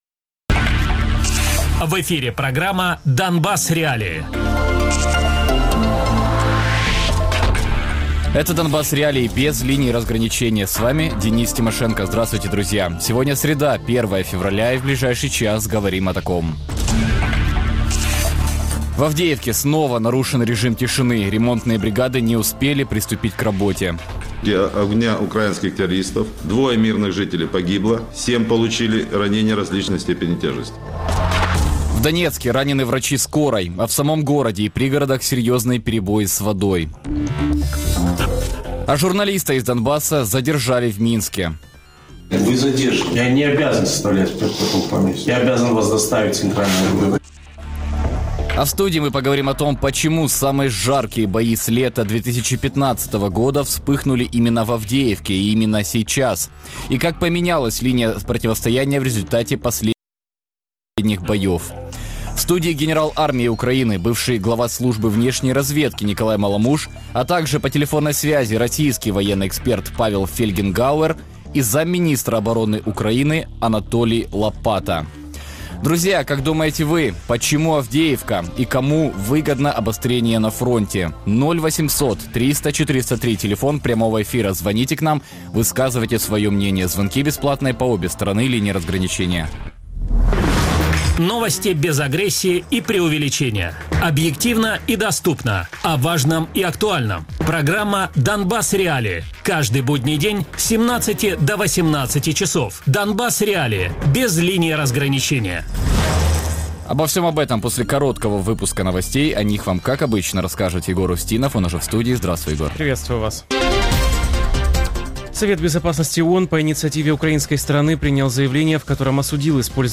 Гости: Николай Маломуж, генерал армии Украины, бывший глава Службы внешней разведки Украины Юрий Бутусов, военный эксперт, главный редактор сайта "Цензор. НЕТ" Радіопрограма «Донбас.Реалії» - у будні з 17:00 до 18:00. Без агресії і перебільшення. 60 хвилин про найважливіше для Донецької і Луганської областей.